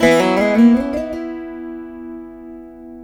158B VEENA.wav